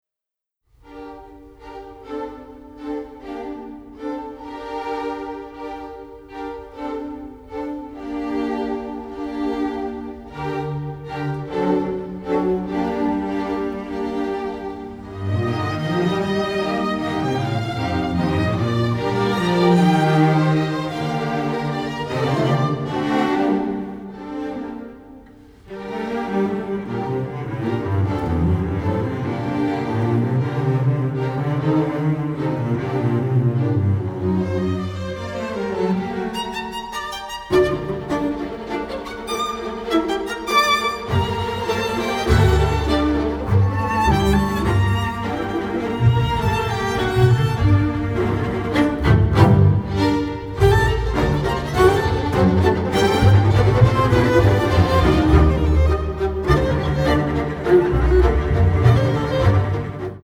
Recorded at Air Studios Lyndhurst Hall in London